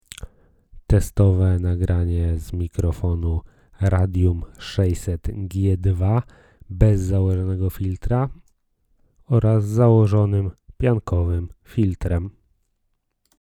Dla mnie dźwięk uzyskany z mikrofonu po wyjęciu z pudełka i podłączeniu do komputera, bez żadnej dalszej obróbki, jest wystarczająco dobry do wszystkich zastosowań. Poniżej dołączyłem próbkę nagrania zarejestrowaną przez applowski Garage Band na standardowych ustawieniach. Dla porównania nagrałem także fragment głosu z założoną gąbką. Wyraźnie słychać, jak tłumi ona głoski wybuchowe i wszelkie mlaskania.